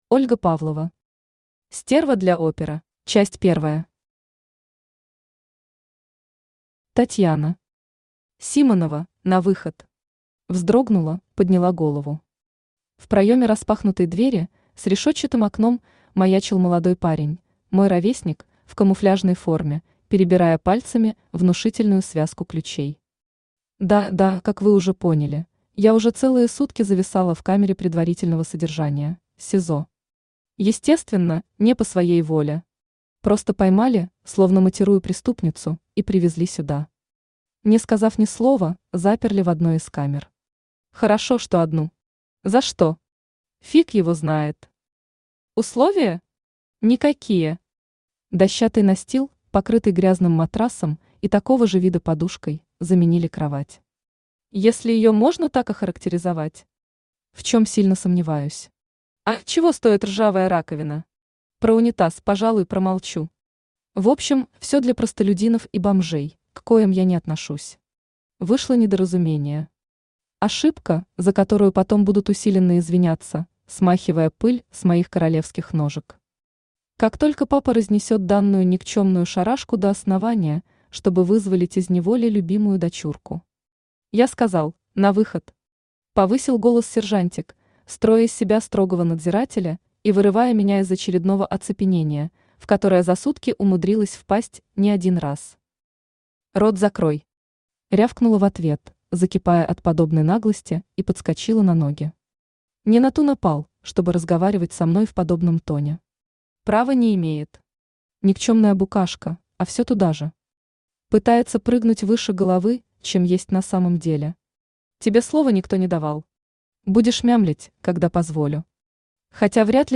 Аудиокнига Стерва для опера | Библиотека аудиокниг
Aудиокнига Стерва для опера Автор Ольга Анатольевна Павлова Читает аудиокнигу Авточтец ЛитРес.